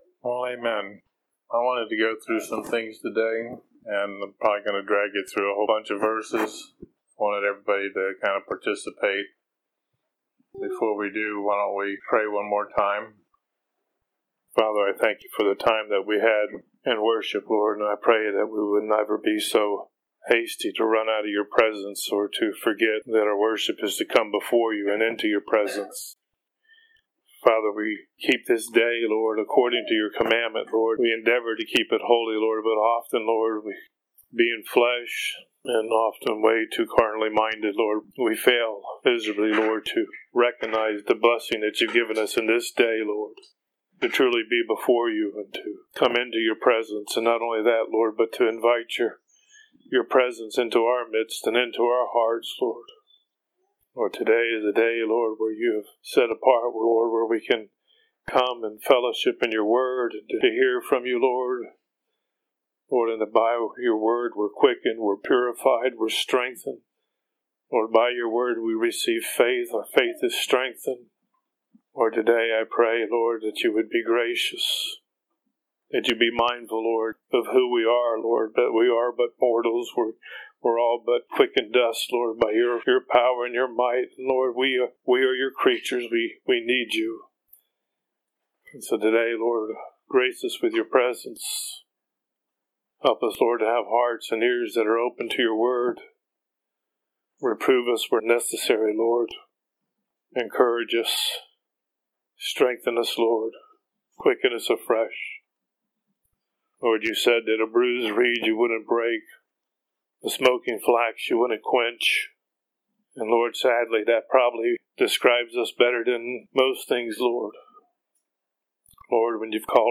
Message 10/30/2016